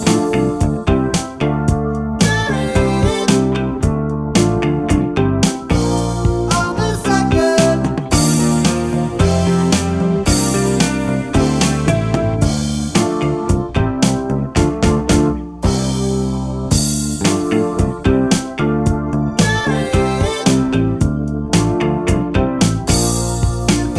Minus Guitars And Sax Pop (1960s) 3:45 Buy £1.50